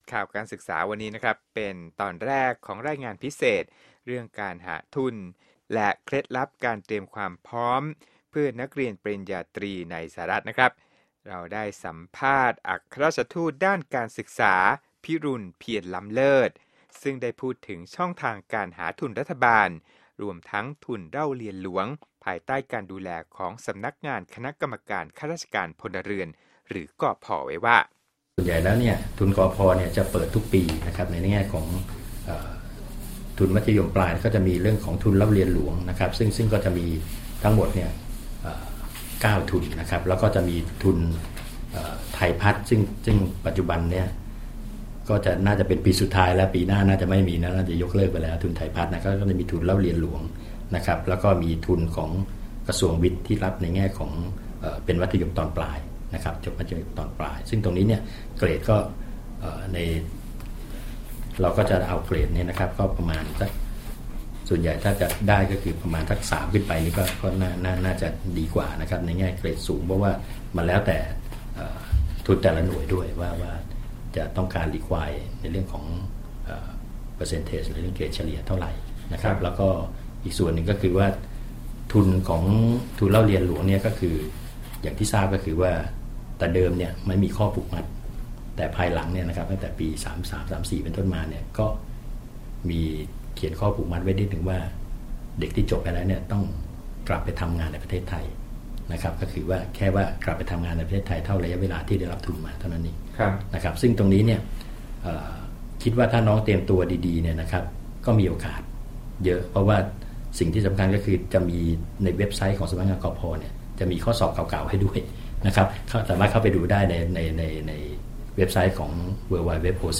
สัมภาษณ์พิเศษอัครราชทูตฝ่ายการศึกษา ประจำกรุงวอชิงตัน พิรุฬ เพียรล้ำเลิศ ซึ่งแนะนำช่องทางของทุน